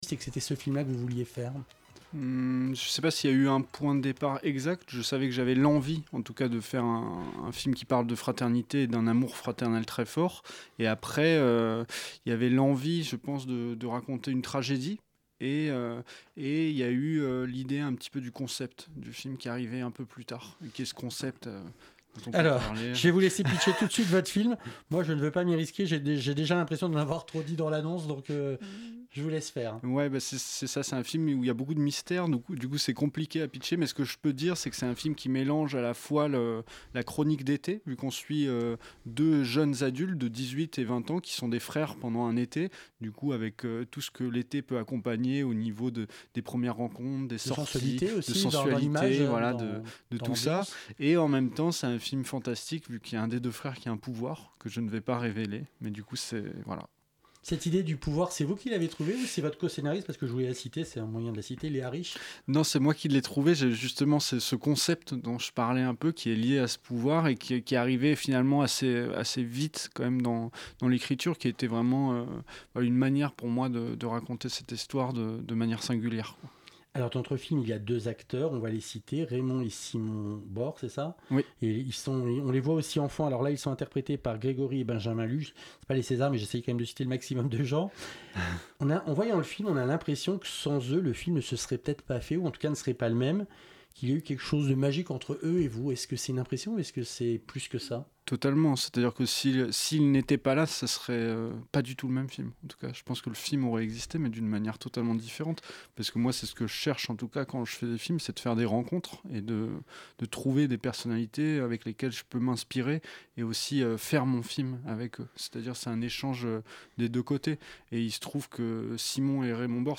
Les premières secondes de l'émission sont manquantes.. mais sauf si vous adorez notre générique.. ce n'est pas très grave..